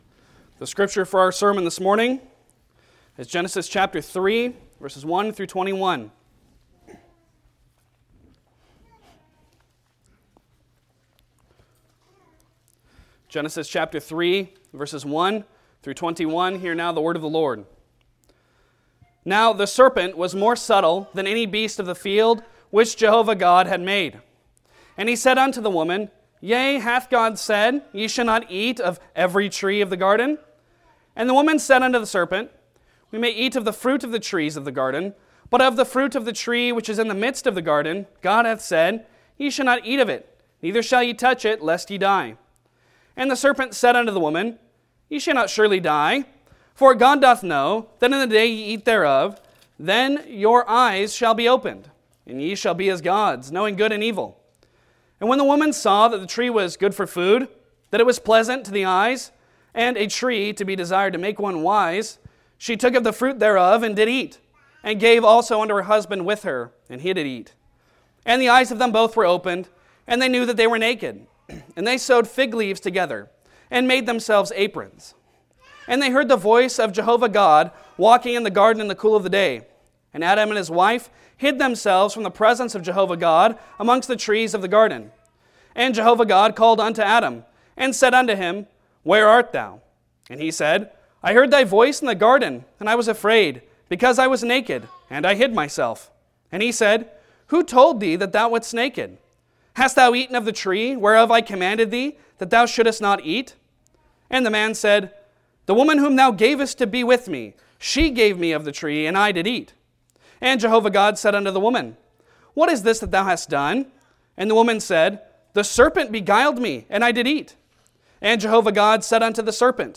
Passage: Genesis 3:1-21 Service Type: Sunday Sermon